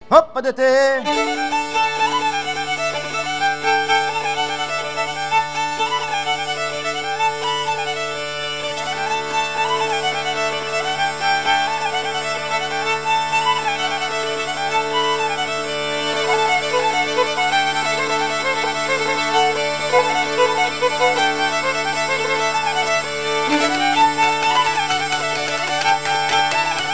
danse : pas d'été
Pièce musicale éditée